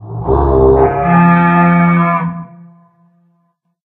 horn_celebrate.ogg